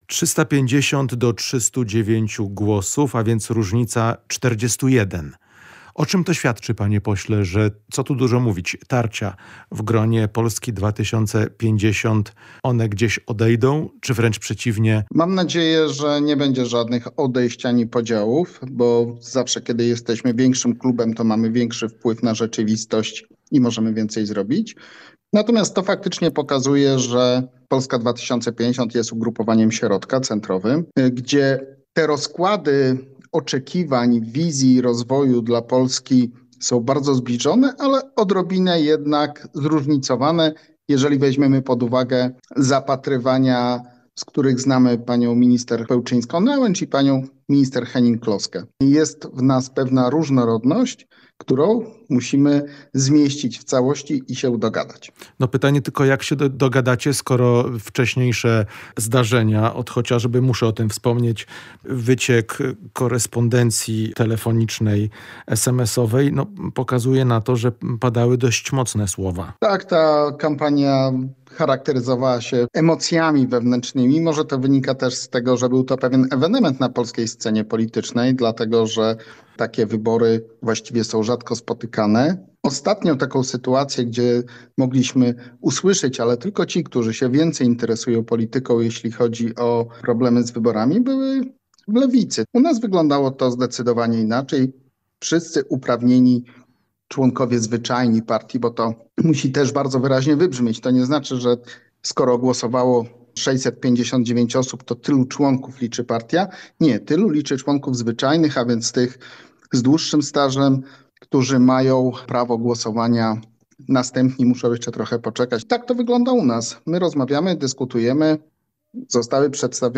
Rozmowa z posłem Sławomirem Ćwikiem